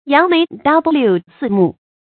扬眉眴目 yáng méi shùn mù 成语解释 横眉怒目。
成语注音 ㄧㄤˊ ㄇㄟˊ ㄕㄨㄣˋ ㄇㄨˋ